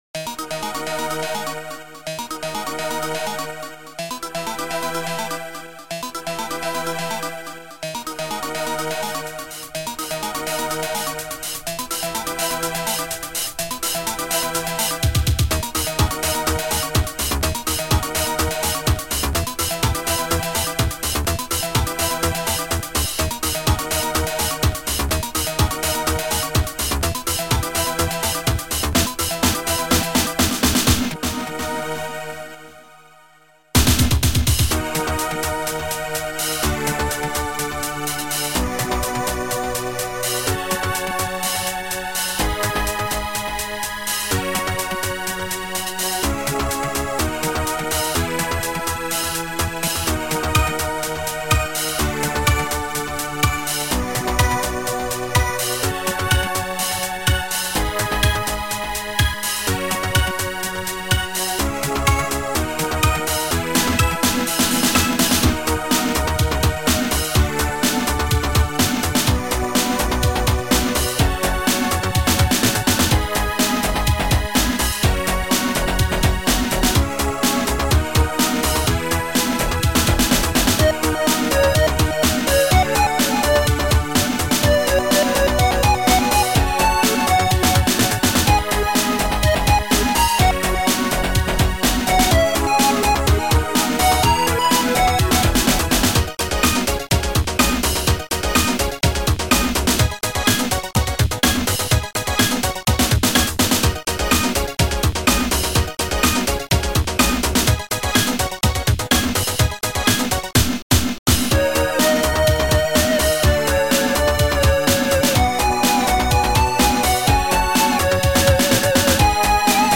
Sound Format: Noisetracker/Protracker
Sound Style: Ambient